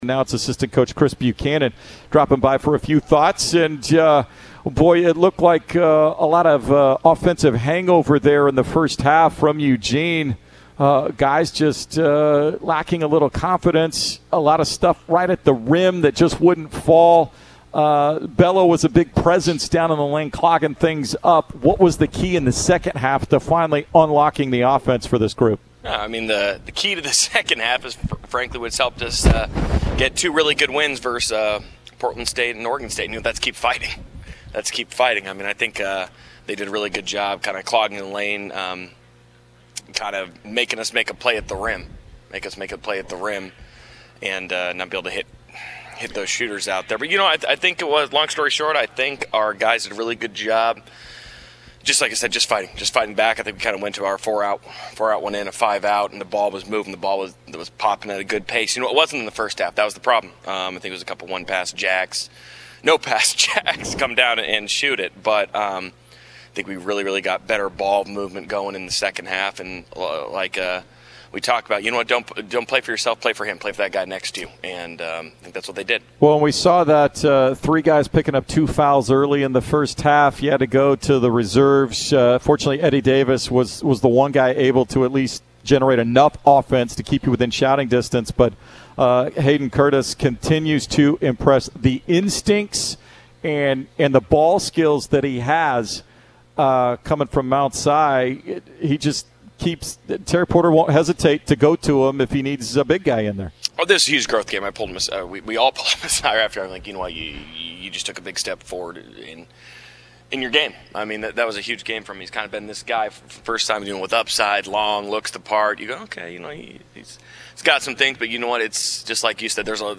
Post-Game Interview vs. Montana State